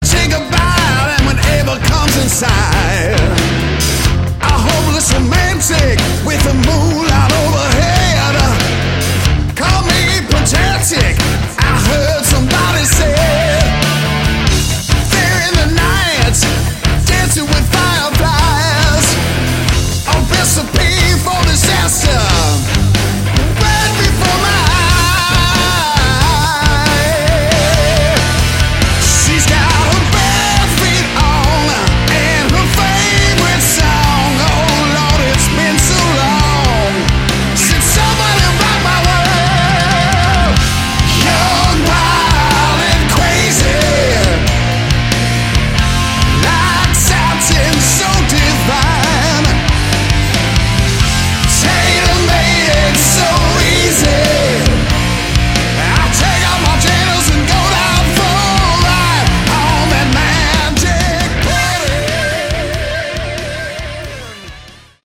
Category: Hard Rock
Vocals
Drums
Guitar